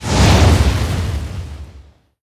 irongolem_attack11_chargebeam_03_fire.ogg